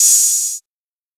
JJHiHat (6).wav